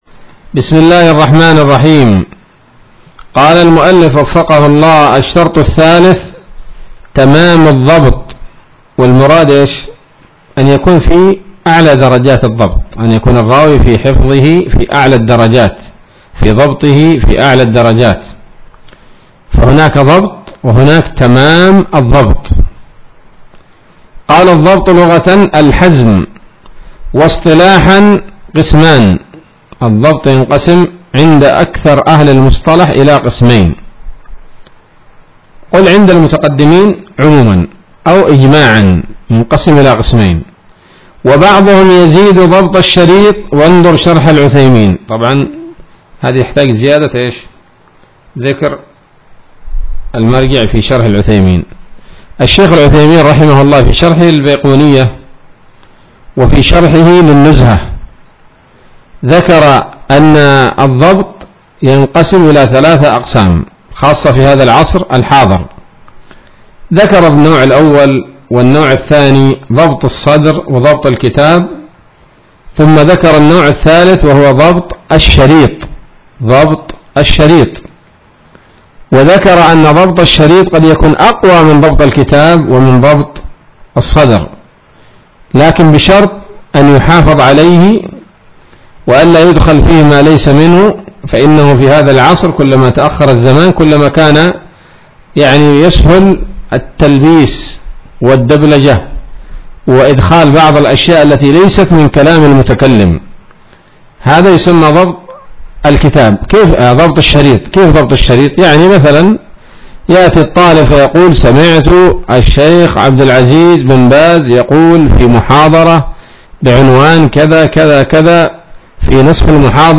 الدرس السادس من الفتوحات القيومية في شرح البيقونية [1444هـ]